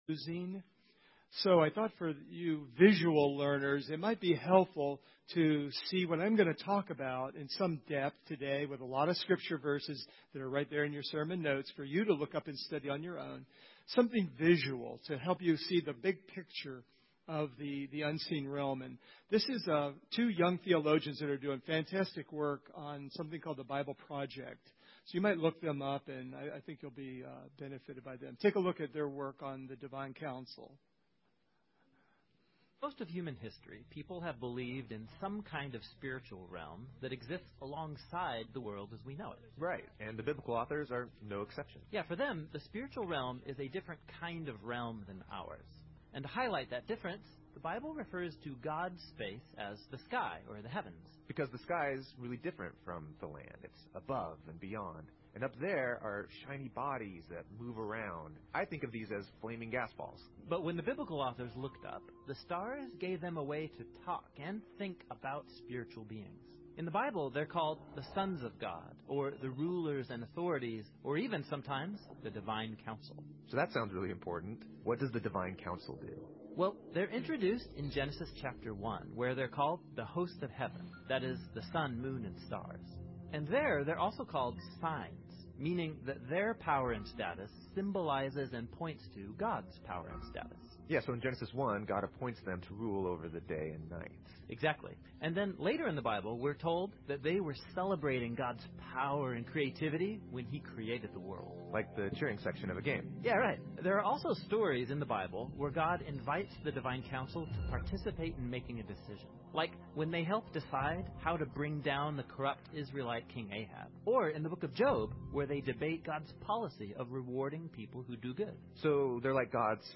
That is because supernatural entities stand behind governments and media influencing for evil. I will be discussing this in the sermon 'The Unseen Realm'.
1 John 5:21 Service Type: Sunday Morning Do you wonder why there is so much evil in the world?